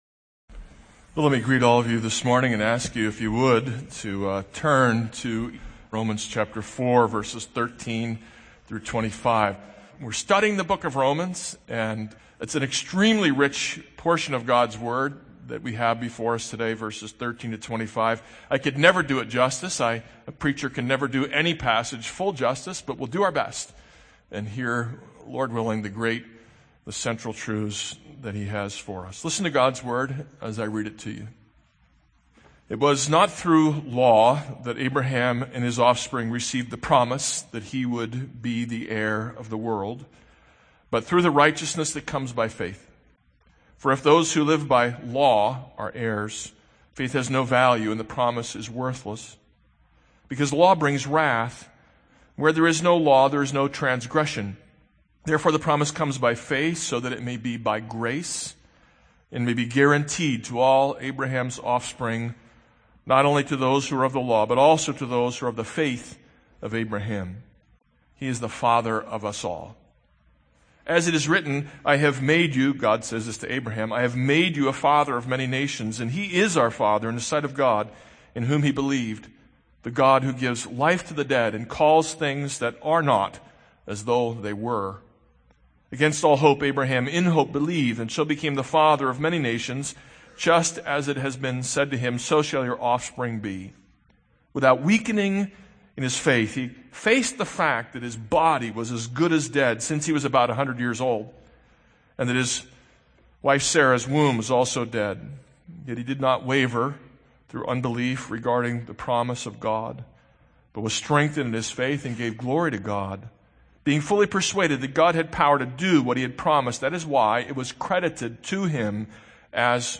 This is a sermon on Romans 4:13-25.